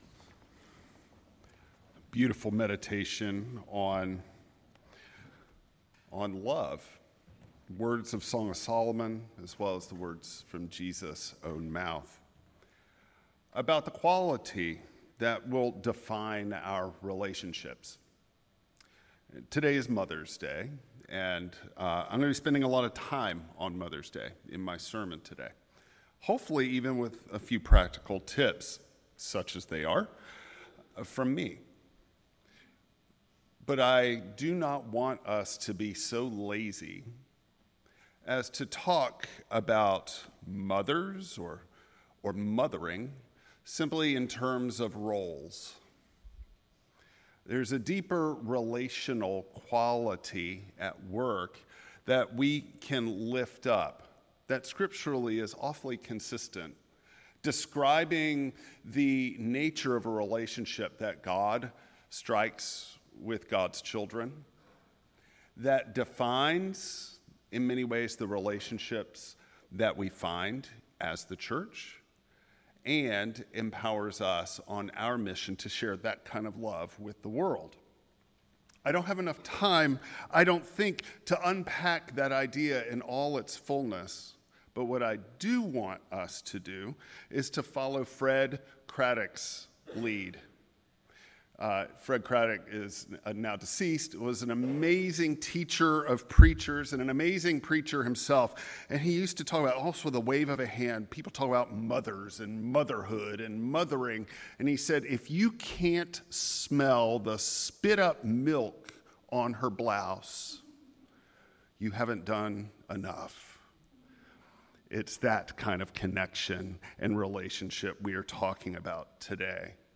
Preacher
Service Type: Traditional Service